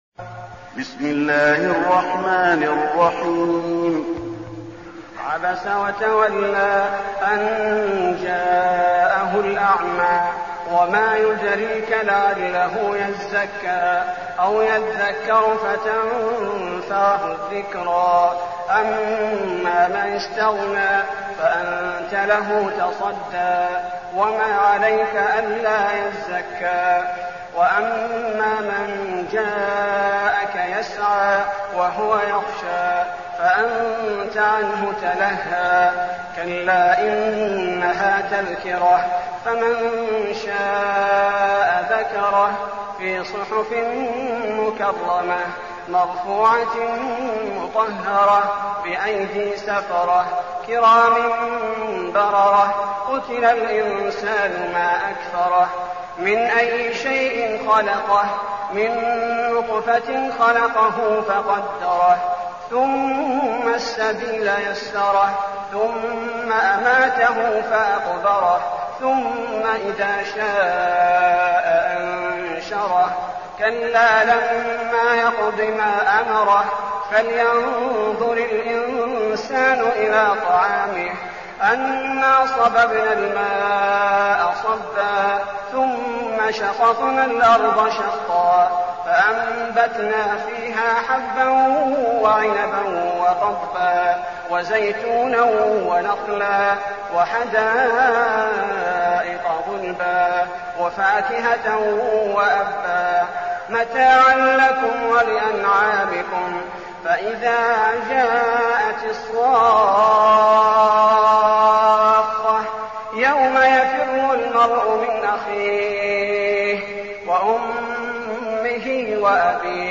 المكان: المسجد النبوي عبس